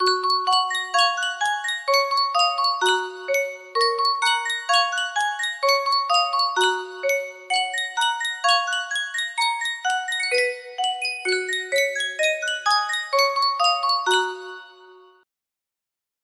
Yunsheng Music Box - Comin' Throu' the Rye 6157 music box melody
Full range 60